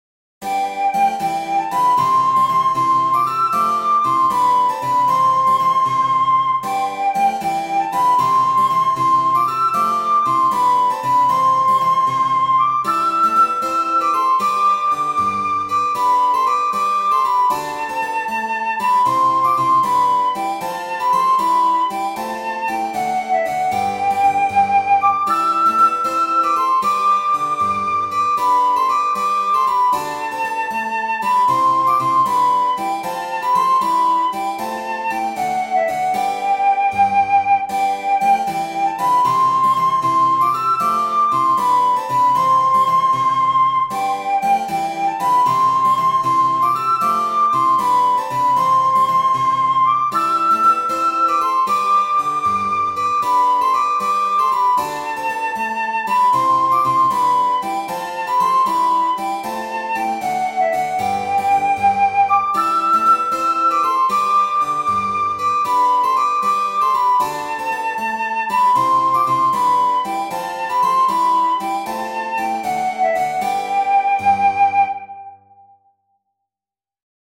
This Folk Dance arrangement is for Recorder and Keyboard.
recorder and piano